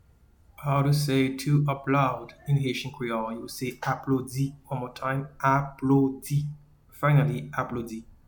Pronunciation:
to-Applaud-in-Haitian-Creole-Aplodi.mp3